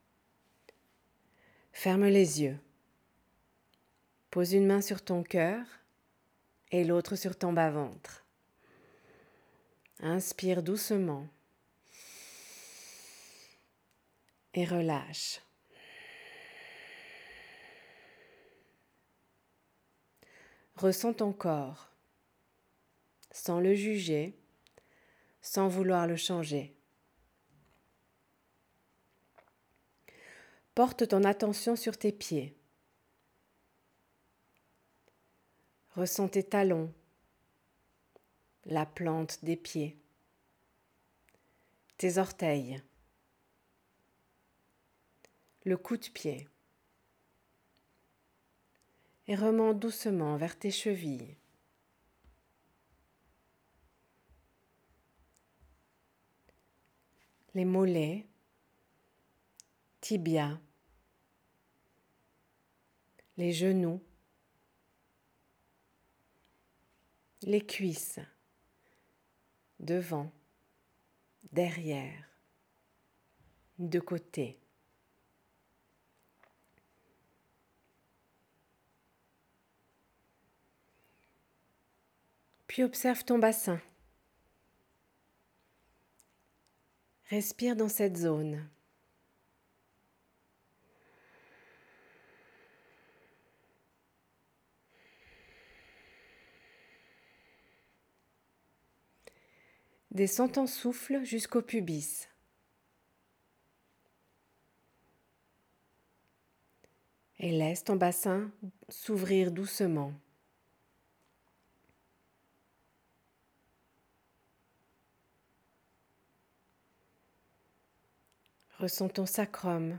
Une pratique guidée pour calmer ton système nerveux en 10 minutes.
Body-Scan-5-mn.wav